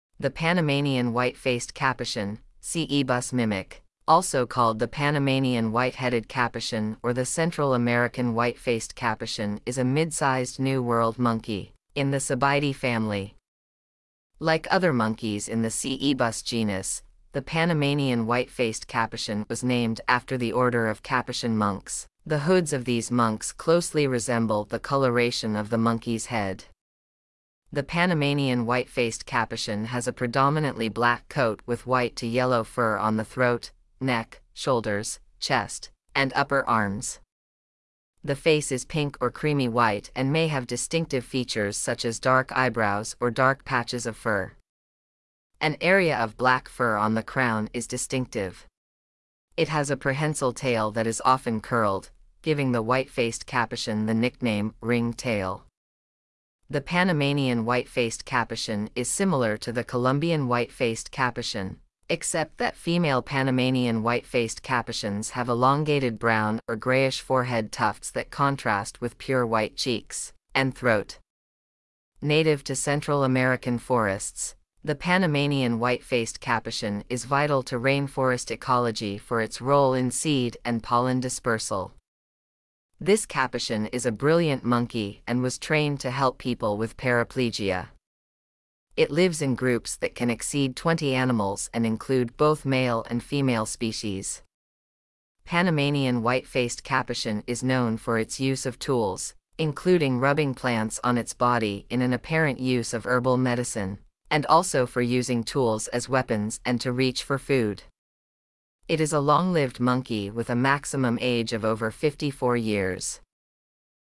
• Common Name: Panamanian White-faced Capuchin
Panamanian-White-faced-Capuchin.mp3